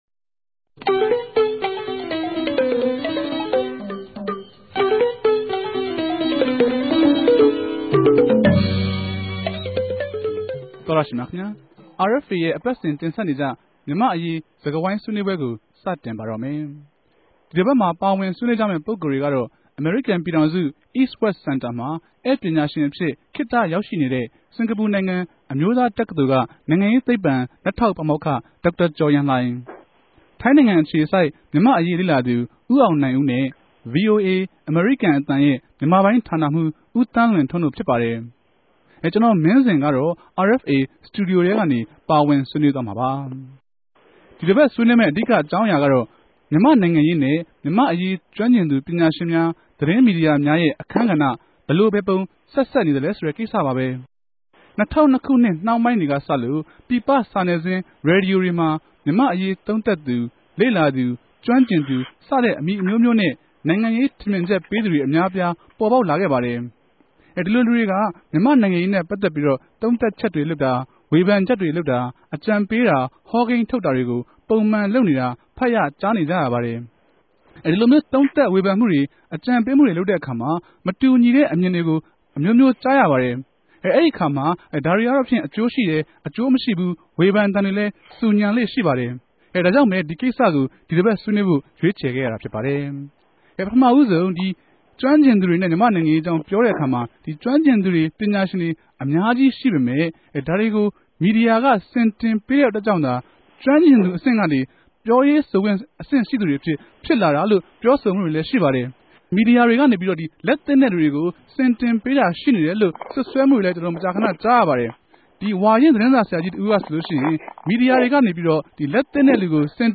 RFA ရဲ့ အပတ်စဉ် ူမန်မာ့အရေး ဆြေးေိံြးပြဲ စကားဝိုင်းမြာ ဒီတပတ်တော့ ူမန်မာိံိုင်ငံရေးမြာ ကဋ္ဋမ်းကဵင်သူတေရြဲ့ အခန်းက္ပ အေုကာင်း သုံးသပ်ဆြေးေိံြး ထားုကပၝတယ်။